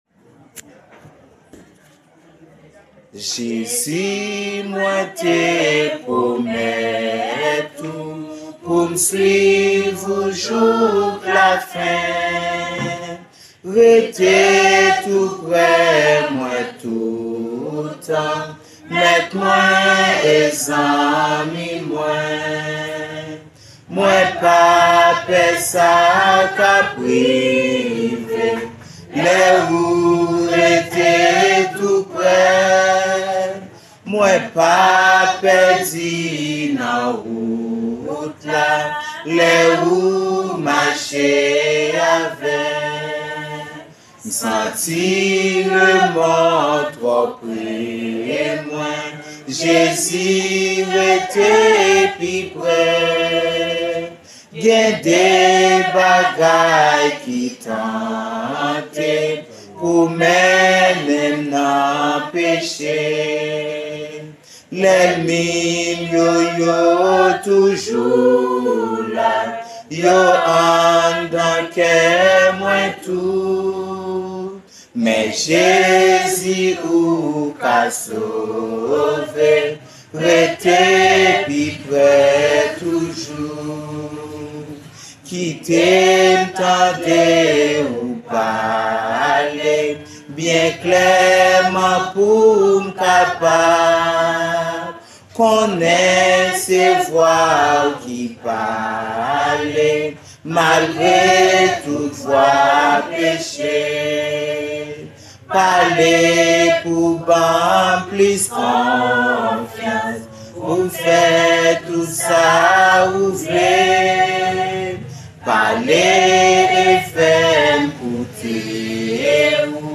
Kreyol song – Jézu, mouin té promèt Ou (Jesus, I Have Promised)